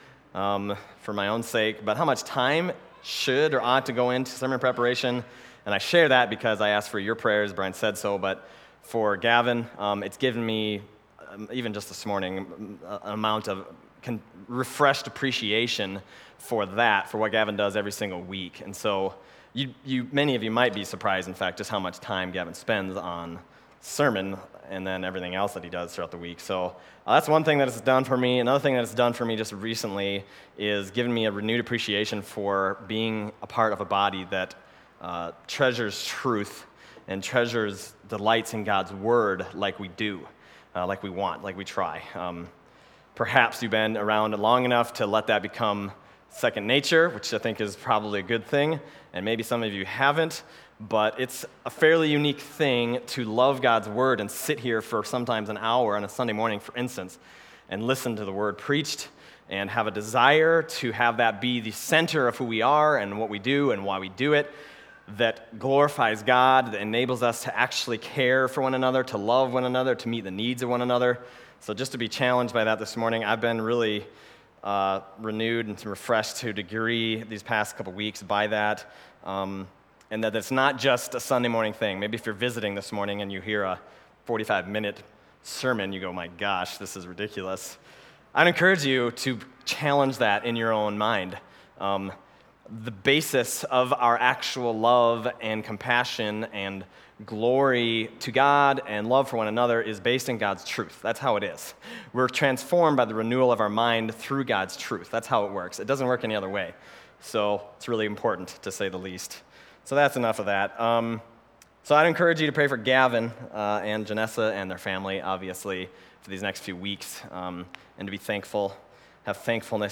Other Passage: Matthew 6:1-4 Service Type: Sunday Morning Matthew 6:1-4 « Be Seen